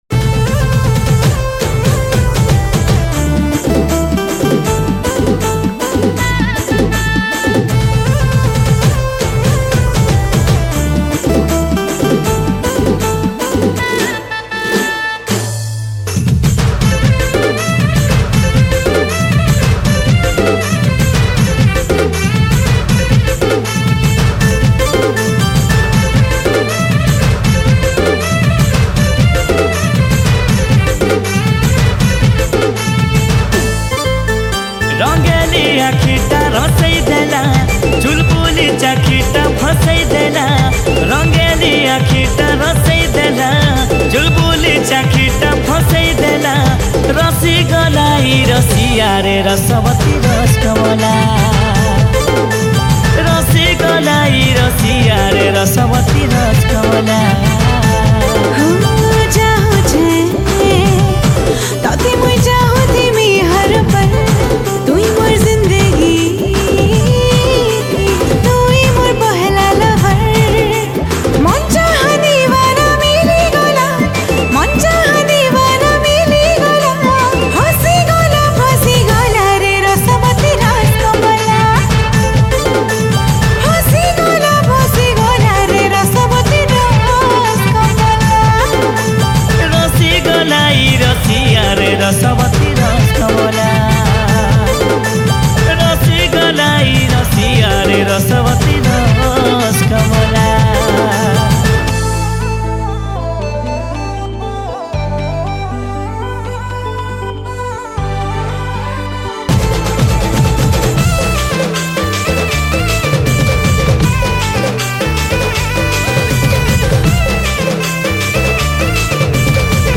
New Sambalpuri Song 2026